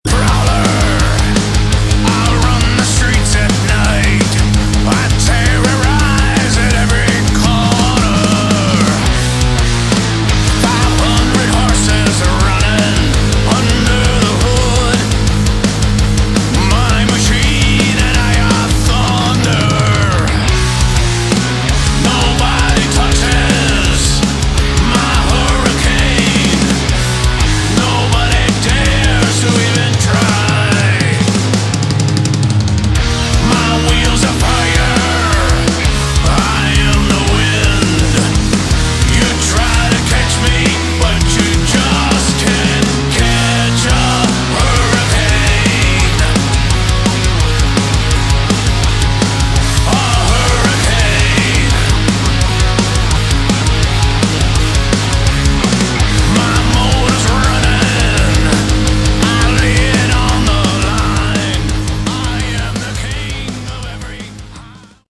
Category: Melodic Metal
guitar, vocals
bass
drums